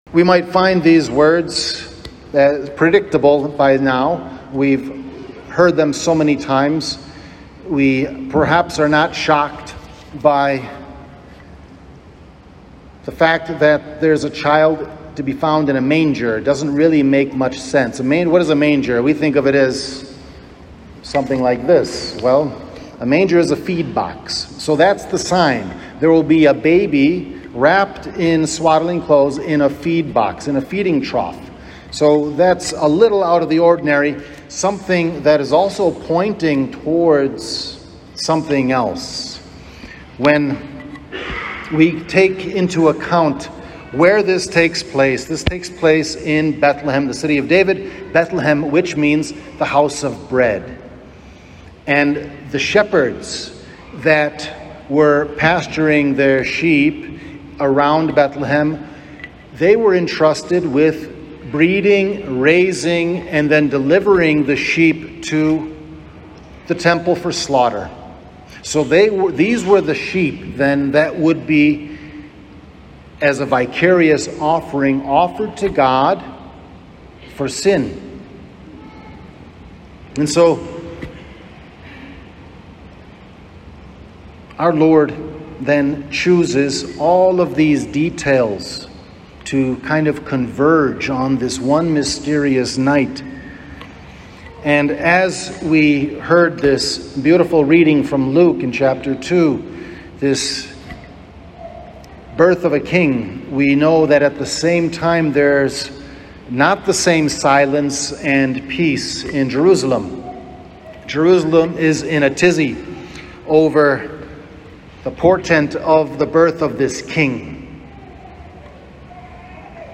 Transcription of Homily